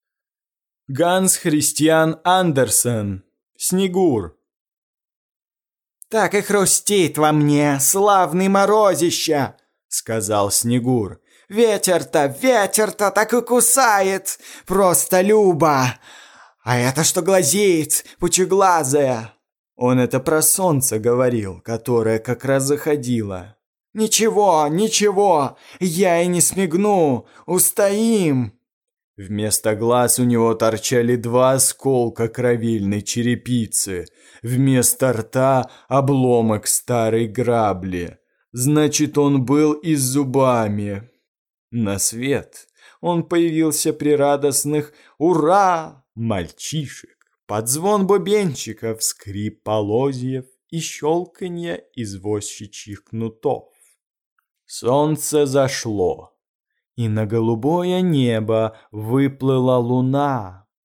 Аудиокнига Снегур | Библиотека аудиокниг